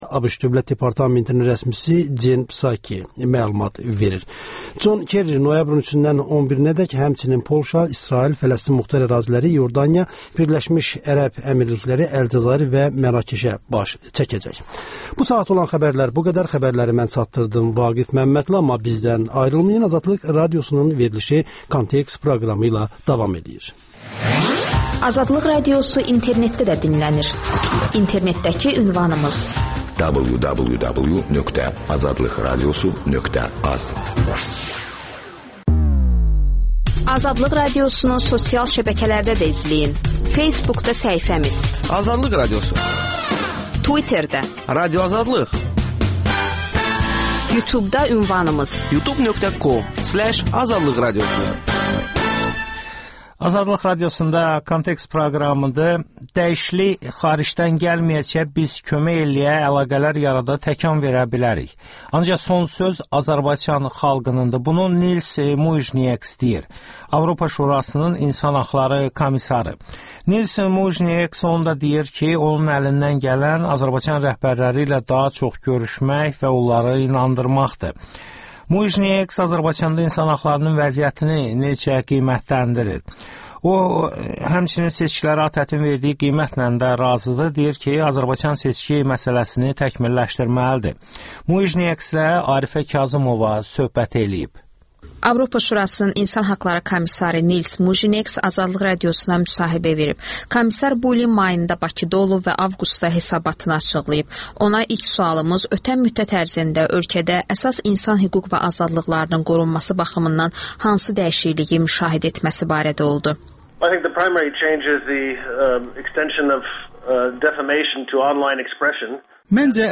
Avropa Şurasının İnsan haqları komissarı Nils Mujniekslə eksklüziv müsahibə.